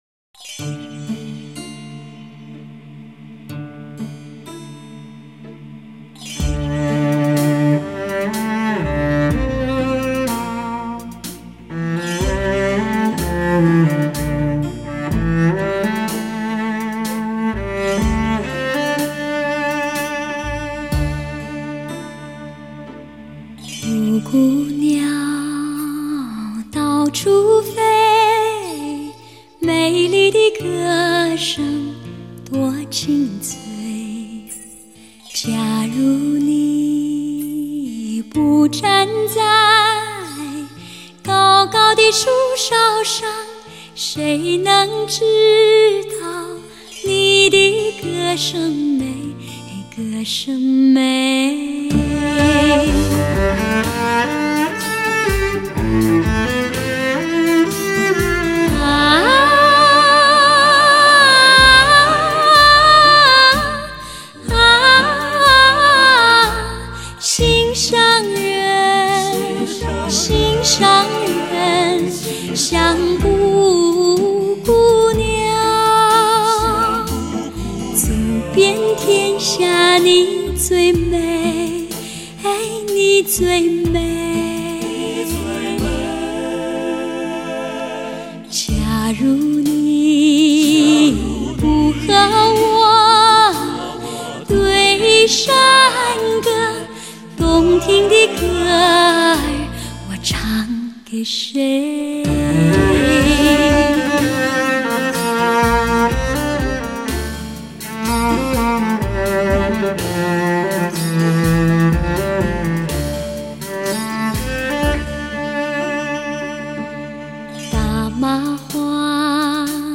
虽然只是一个新名字，音色特别甜美，靓声中又带出一份青春温馨的感觉。
十三首清新的歌曲，令各位听迷悠然神往，舒服地去享受一段美妙的时刻。